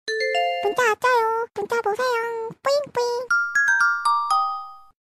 Nada notifikasi WA Korea imut
Genre: Nada dering Korea